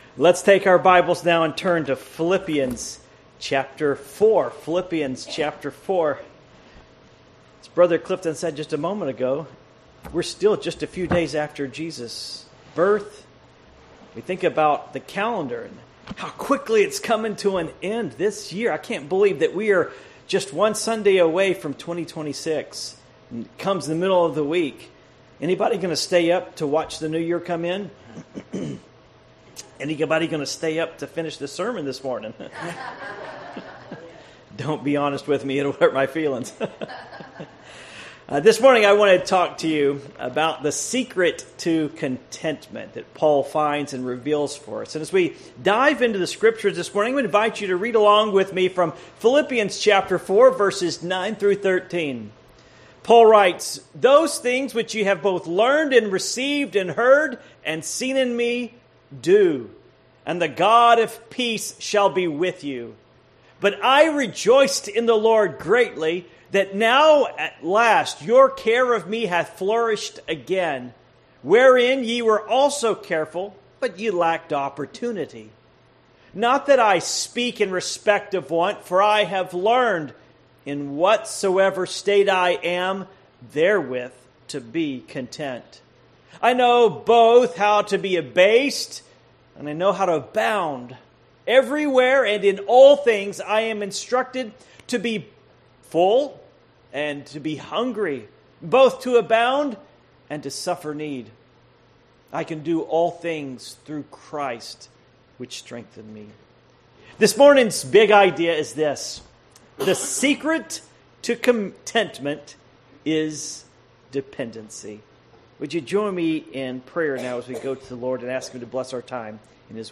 Passage: Philippians 4:9-13 Service Type: Morning Worship